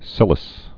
(sĭlĭs)